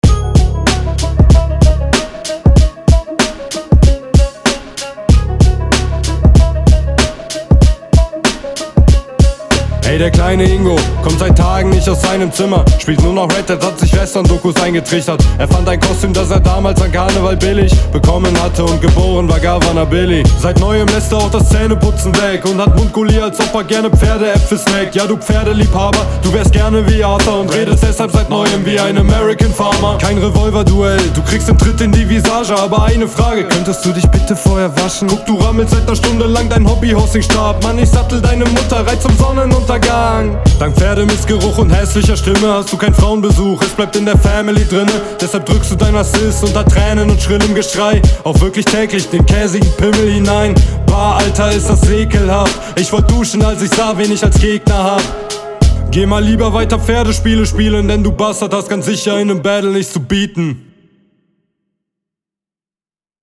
yea lets go, direkt mit nem dopen hipidihiphop beat rein sehr gut. diese arrogante betonung …